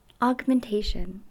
Other common forms: The noun we typically use is "augmentation," pronounced "ogg men TAY shun" ( hear it ).
augmentation.mp3